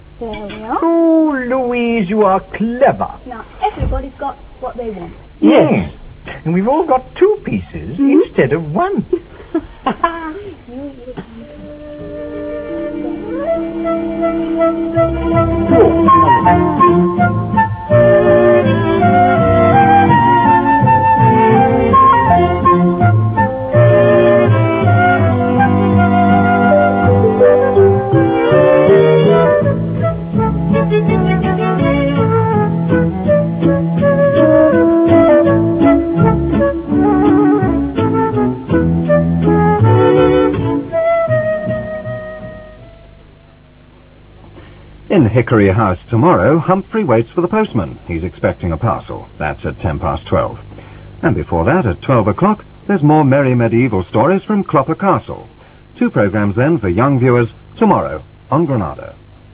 end music